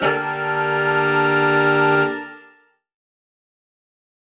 UC_HornSwellAlt_Dsus4min6.wav